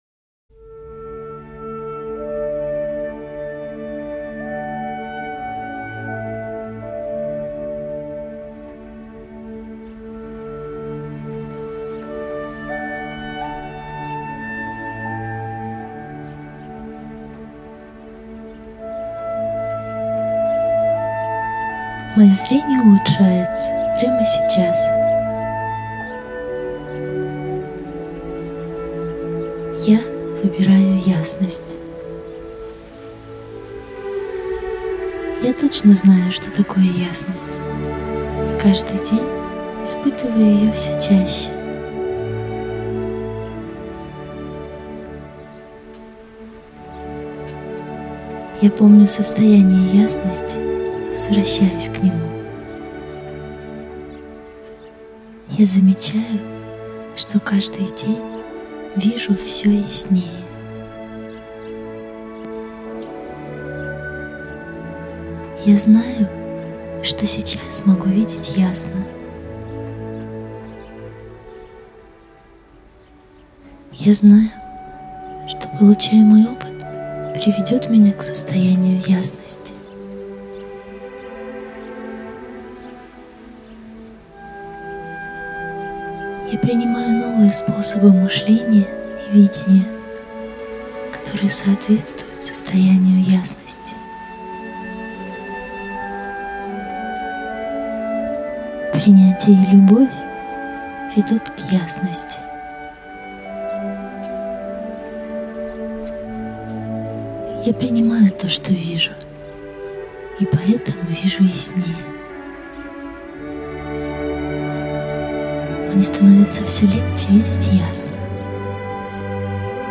музыка: Амадей Моцарт, концерт для фагота с оркестром К.622 — Adagio